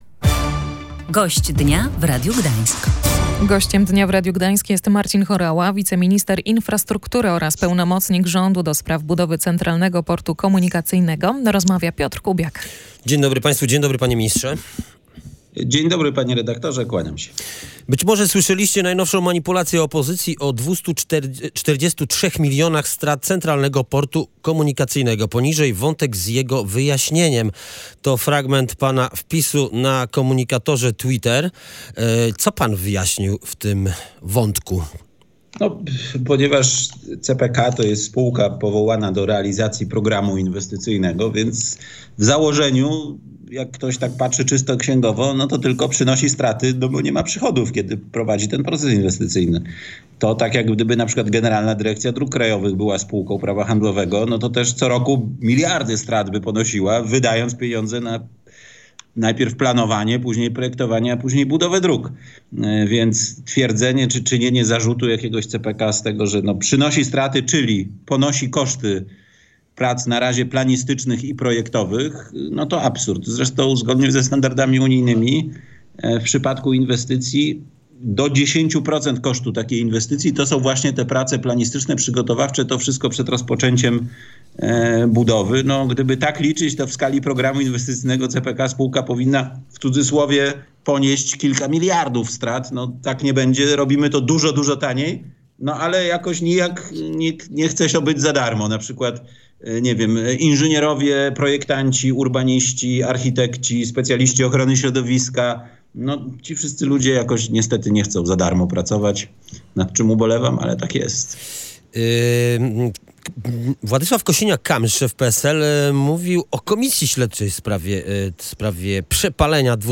Gość Dnia rozmawiał również na antenie o Centralnym Porcie Komunikacyjnym.